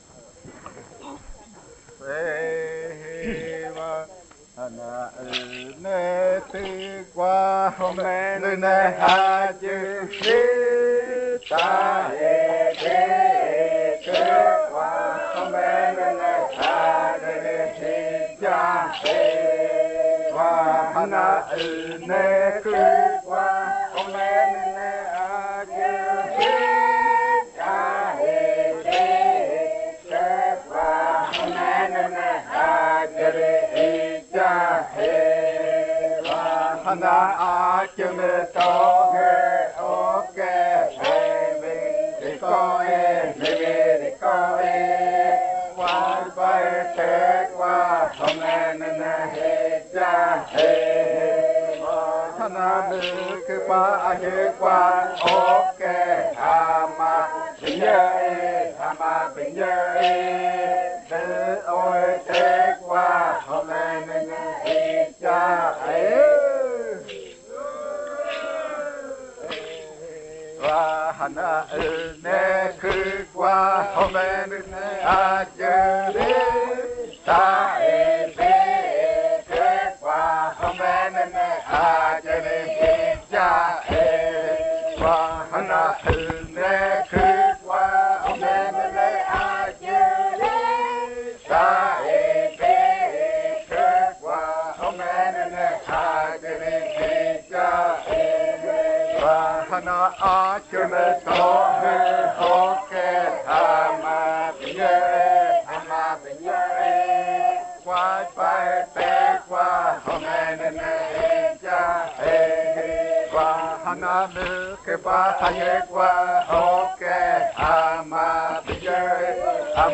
Baile de nombramiento. Canto n°11
Puerto Remanso del Tigre, departamento de Amazonas, Colombia